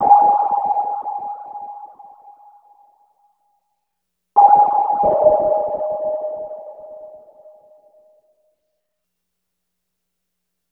Analog Synth Pad .wav